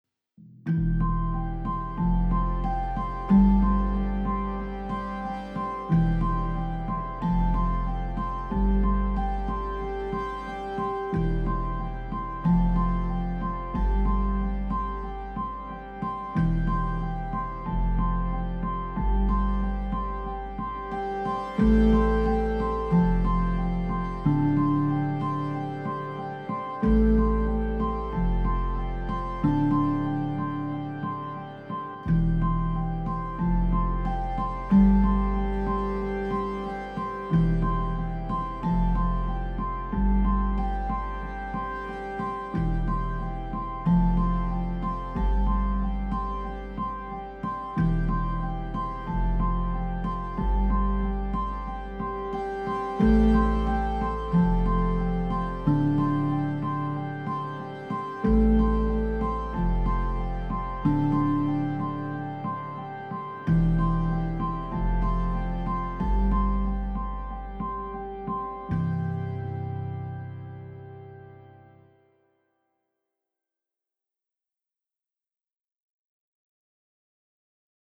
Minimalistic awakening with light drones.